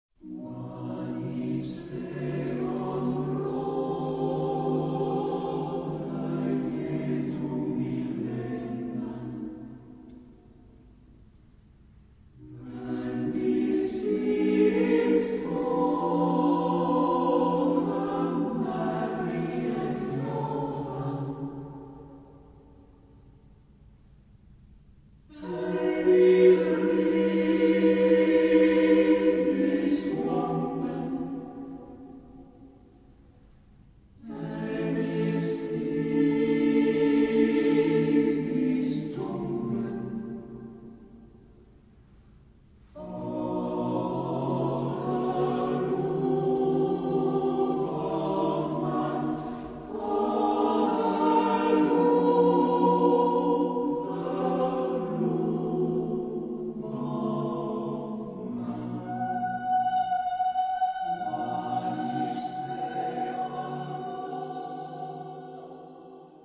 for unaccompanied voices
Genre-Style-Forme : Profane ; Chœur
Type de choeur : SSATB  (5 voix mixtes )